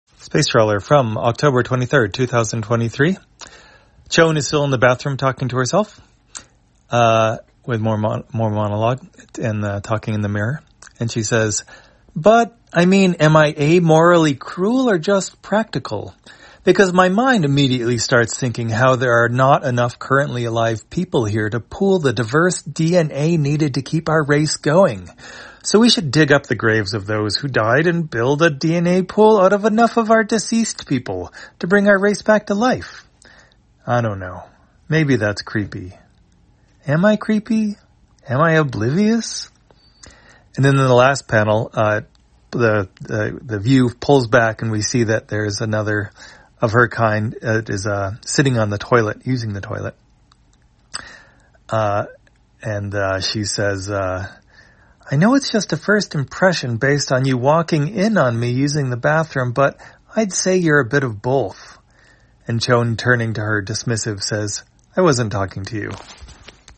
Spacetrawler, audio version For the blind or visually impaired, October 23, 2023.